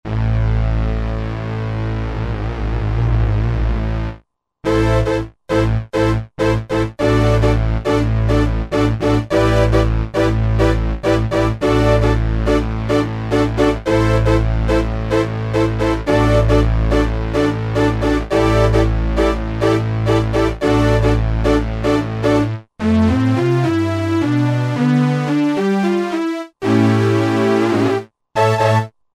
Roland S-760 audio demos
3 JP Brass.mp3